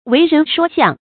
为人说项 wèi rén shuō xiàng
为人说项发音